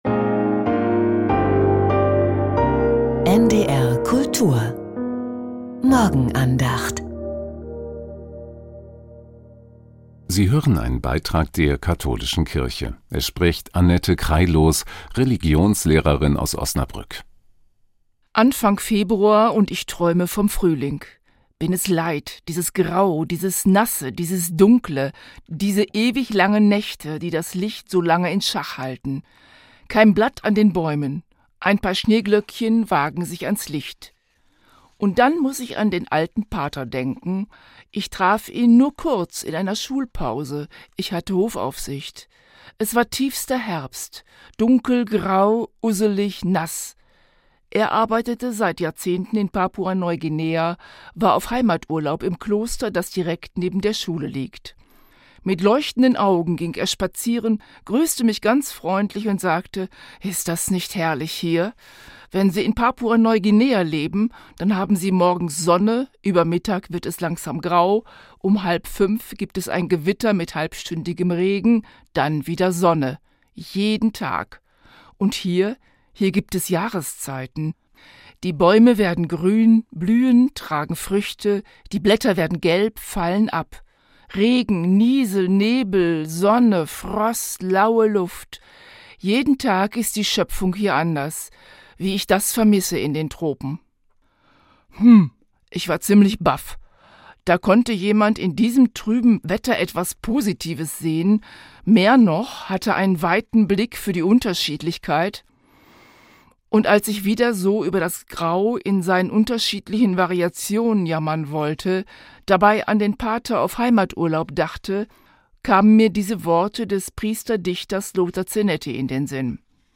Ohne den Wirt ~ Die Morgenandacht bei NDR Kultur Podcast